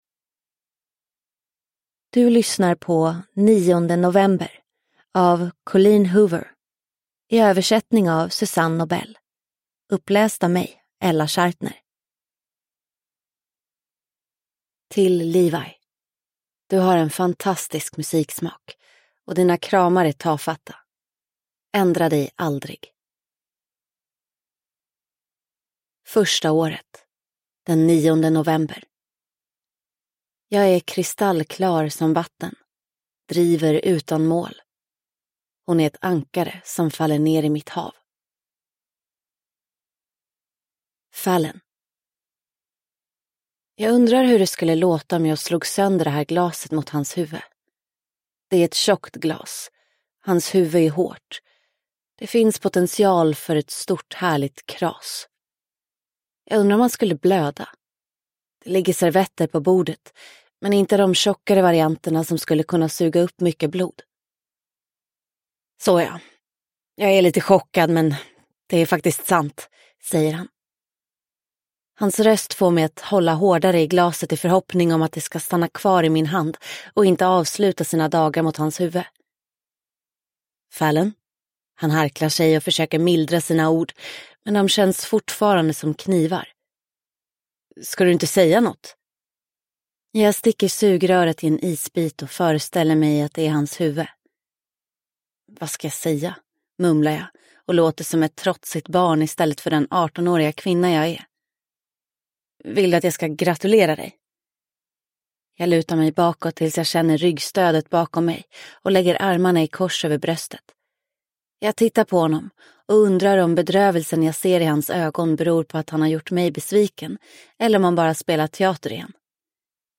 9 november – Ljudbok – Laddas ner